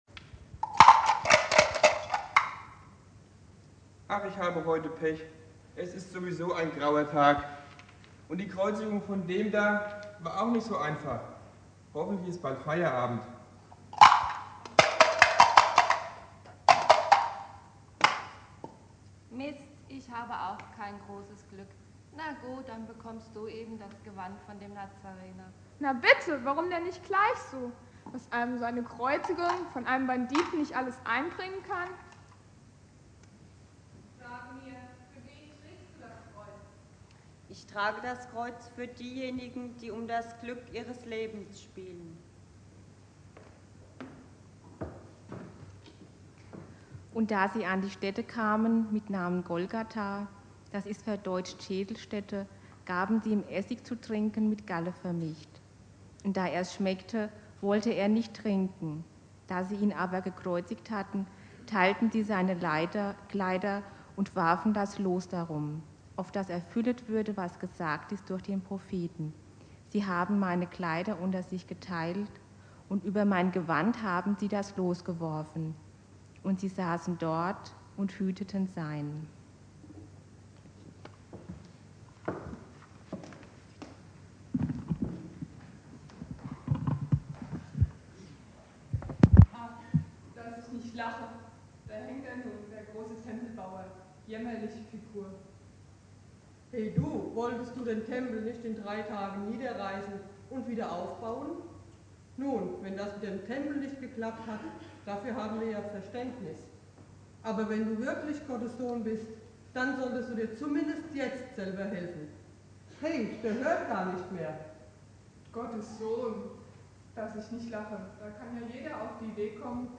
Karfreitag
Thema: Anspiel zur Predigt